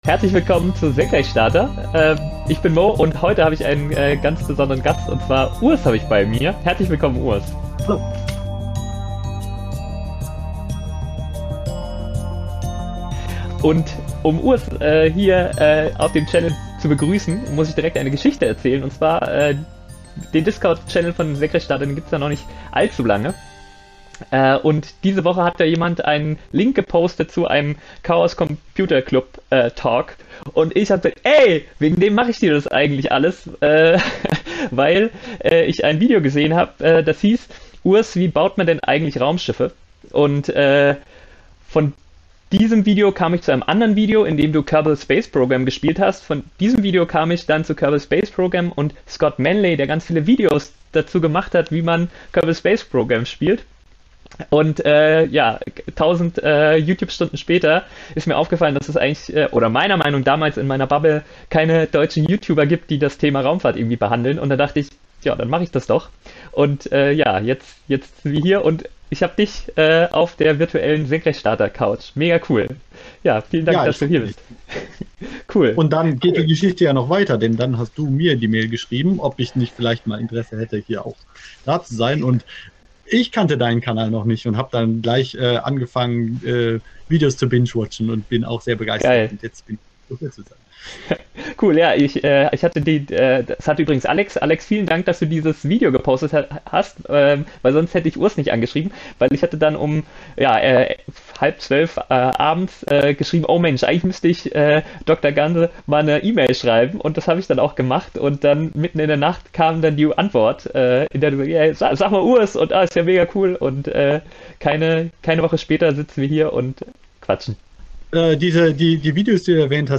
Wir haben in einem Livestream über Weltraummedizin, Microlauncher, Cubesats und natürlich auch über SpaceX und das Starship gesprochen. Hier der erste Teil des Talks.